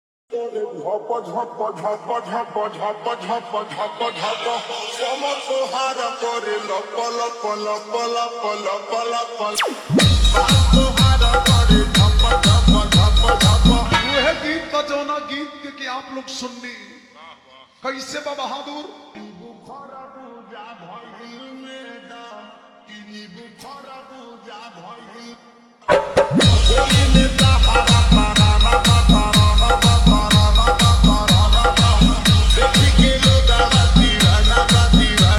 Bhojpuri Songs
(Slowed + Reverb)